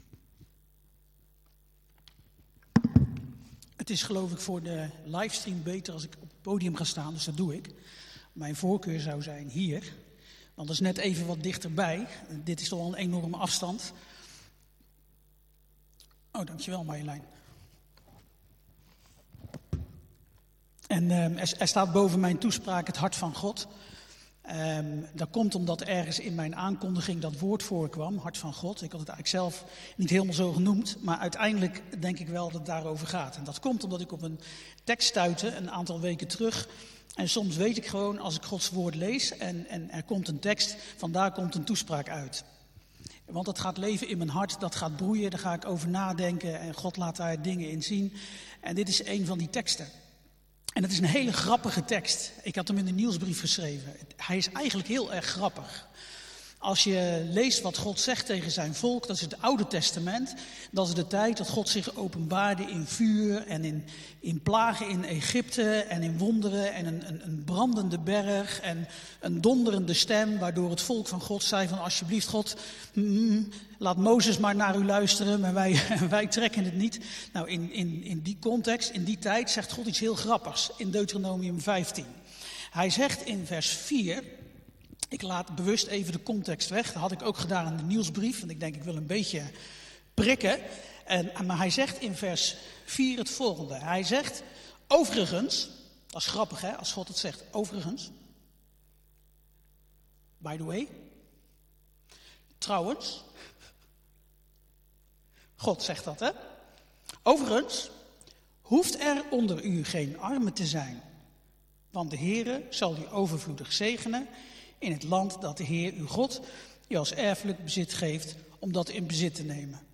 Toespraak 14 augustus: Het hart van God - De Bron Eindhoven